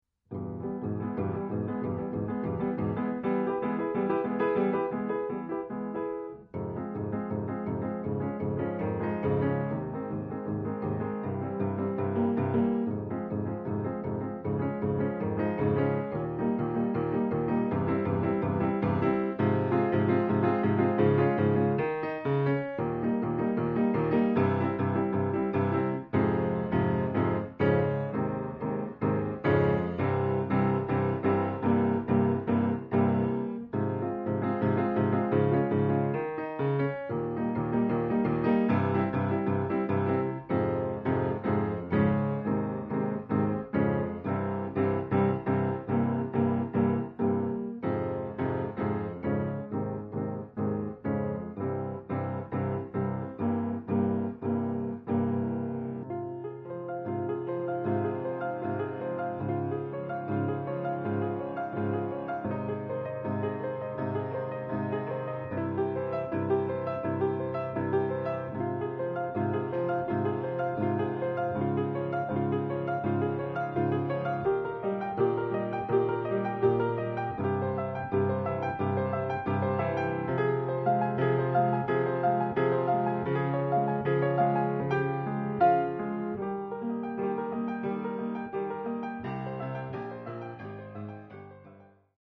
in fa minore